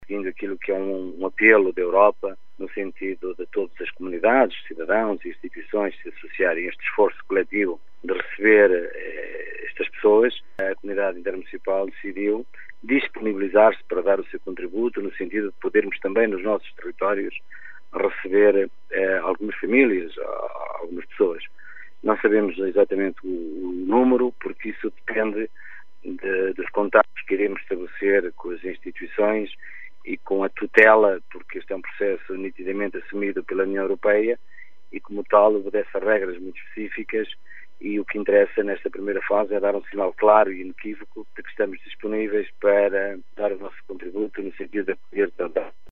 explica o presidente da CIM, Américo Pereira: